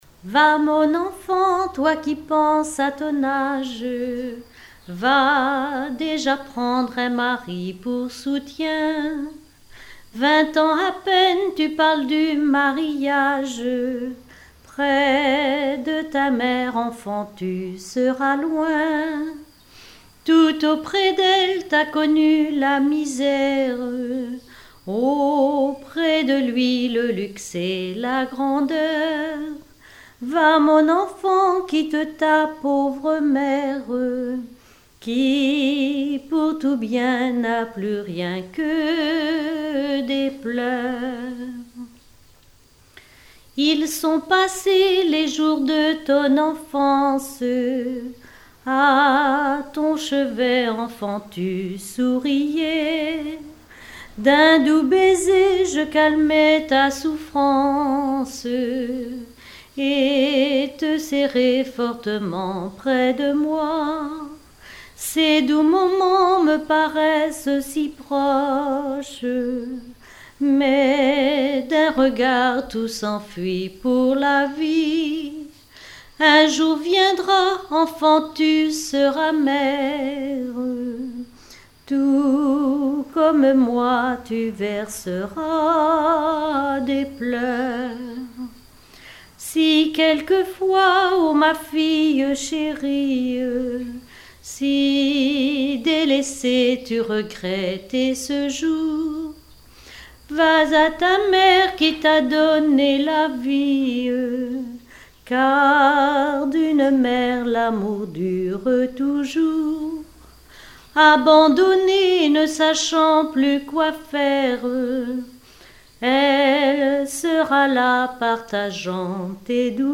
Beauvoir-sur-Mer
Genre strophique
Pièce musicale inédite